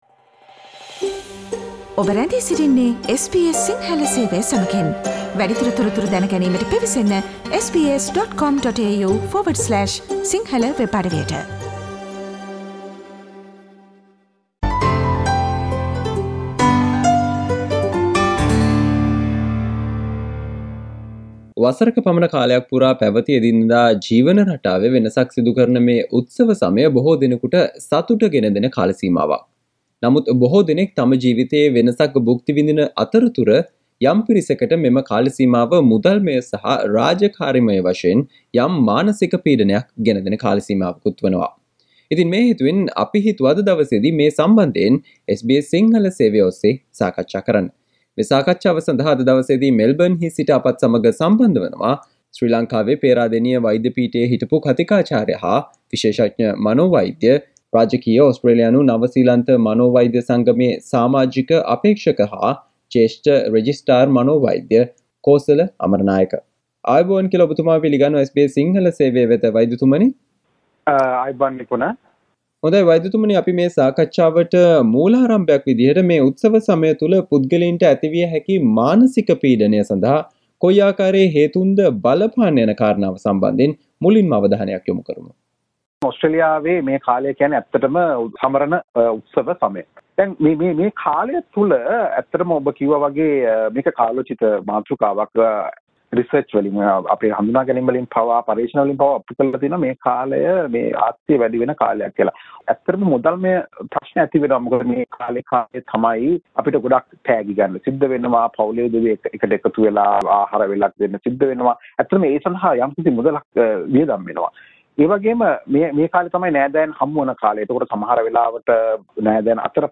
SBS Sinhala discussion on What you need to know to respond to stress during this festive season